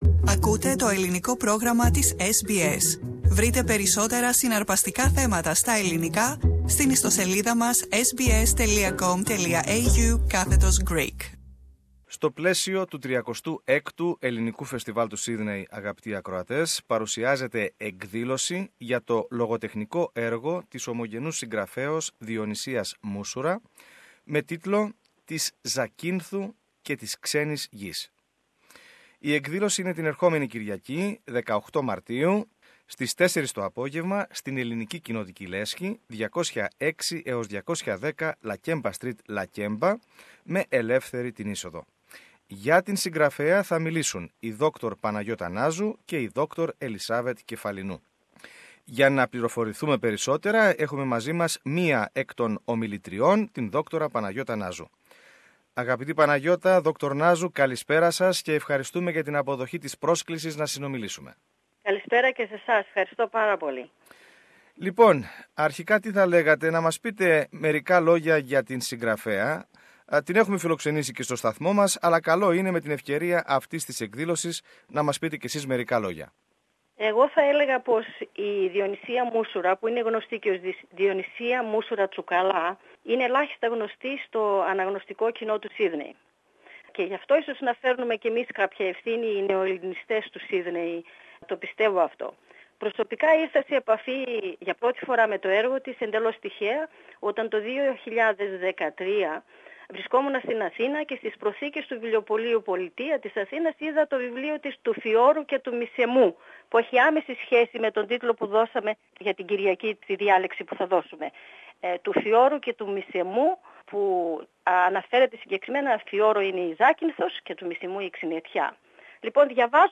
στην συνομιλία